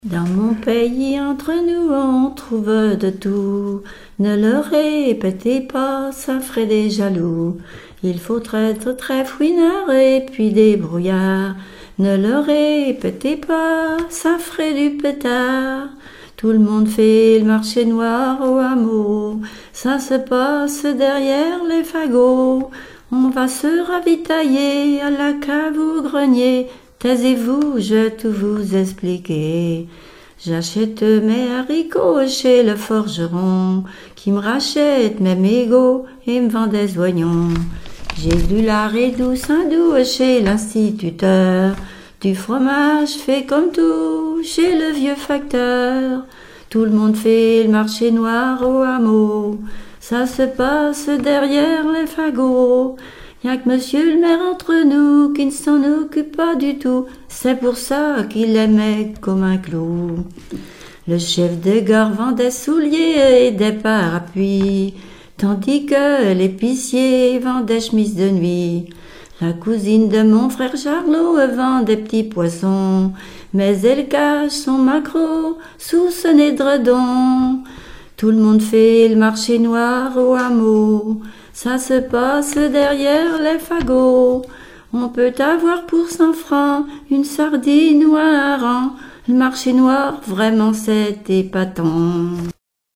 Témoignages sur les conscrits et chansons
Pièce musicale inédite